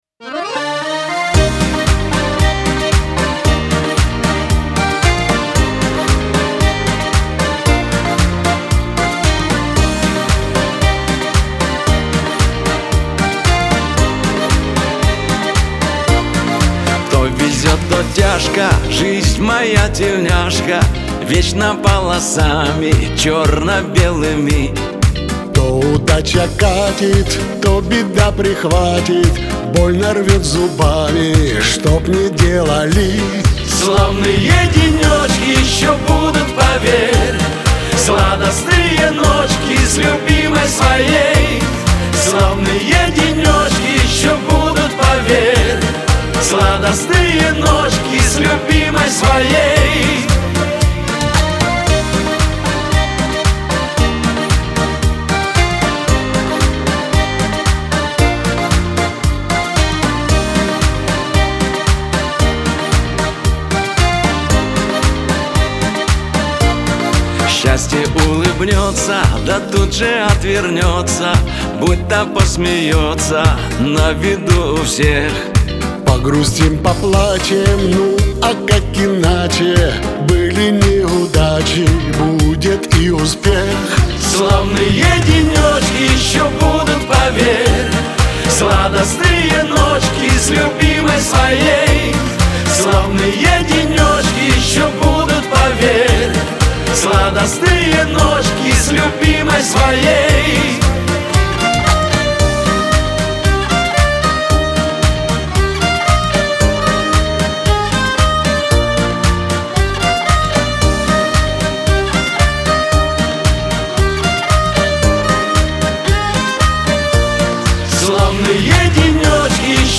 Шансон
Лирика , дуэт